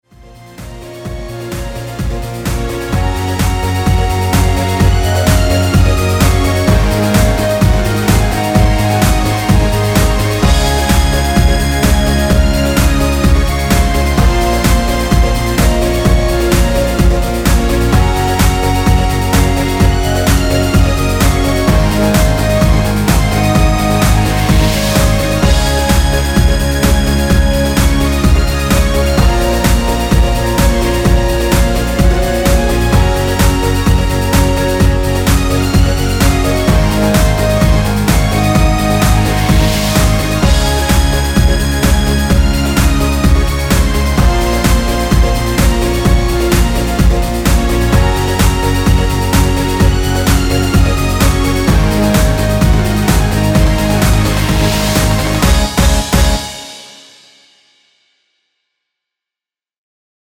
엔딩이 페이드 아웃이라 엔딩을 만들어 놓았습니다.(미리듣기 확인)
◈ 곡명 옆 (-1)은 반음 내림, (+1)은 반음 올림 입니다.
앞부분30초, 뒷부분30초씩 편집해서 올려 드리고 있습니다.